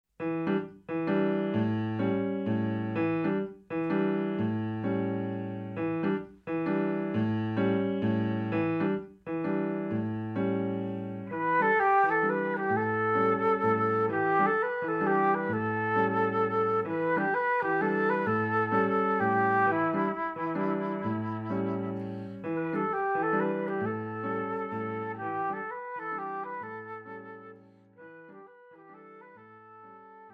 Lehký jazz a pop v úpavě pro flétnu a klavír.
Jednoduché úpravy jsou doprovázeny klavírem.